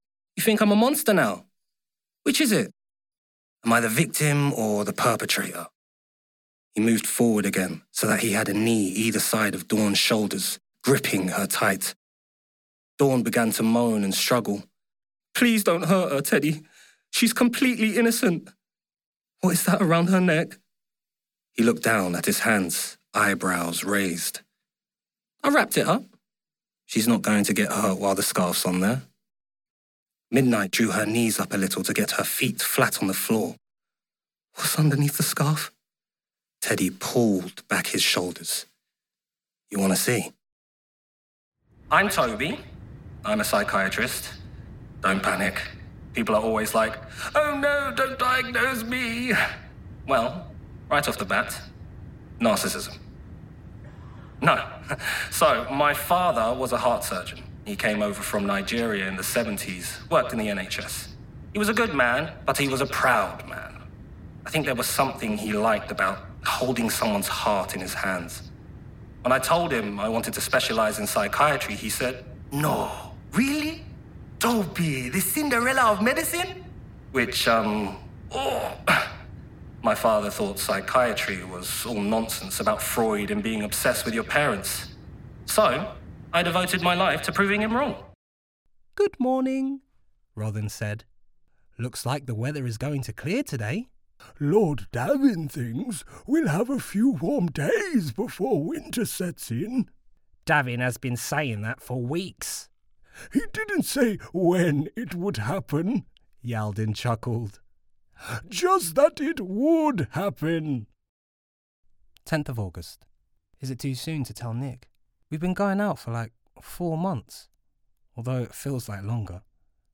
Audiobook Reel
• Native Accents: London, Cockney, Caribbean, Jamaican
The ultimate London boy-next-door, he oozes confidence while imbuing any script with an audible smile.